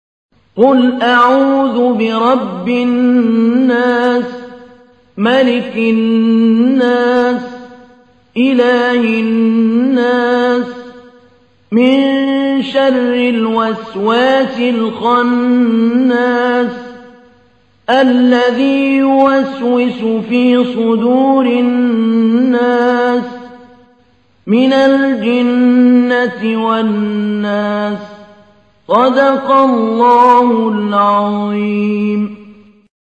تحميل : 114. سورة الناس / القارئ محمود علي البنا / القرآن الكريم / موقع يا حسين